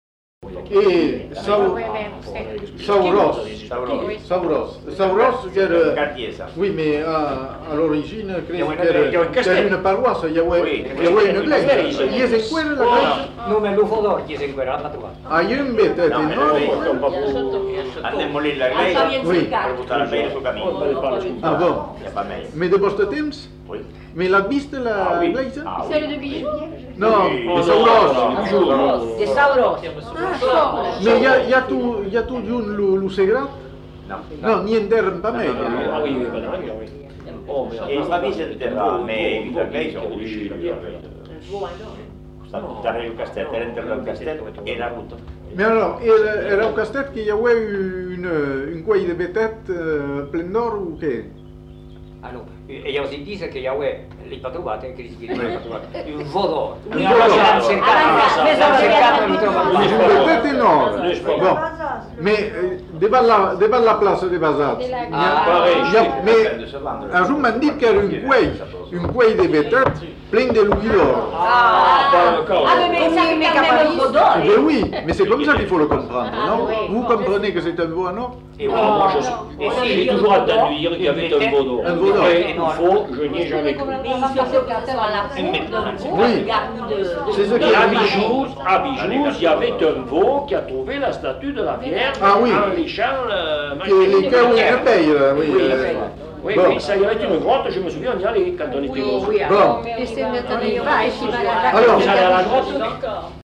Lieu : Bazas
Genre : conte-légende-récit
Type de voix : voix d'homme
Production du son : parlé
Classification : récit légendaire
Notes consultables : Plusieurs interprètes non identifiés.